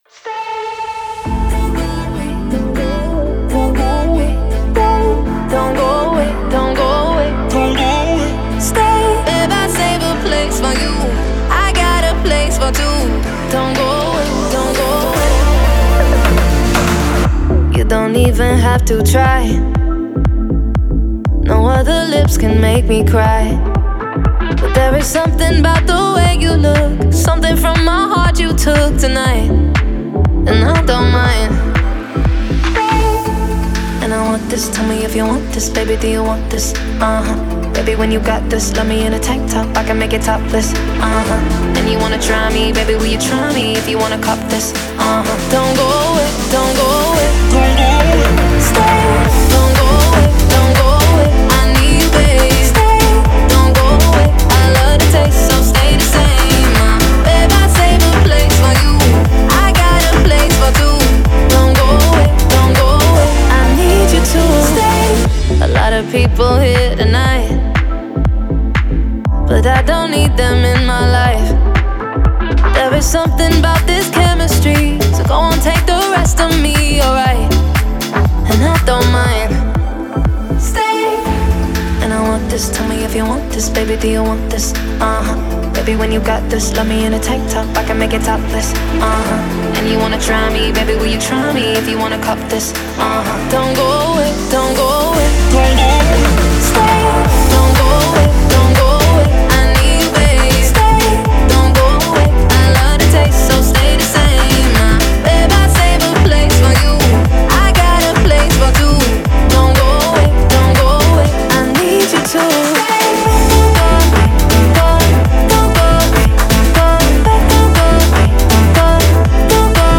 это яркий трек в жанре EDM с элементами поп-музыки
мощные синтезаторы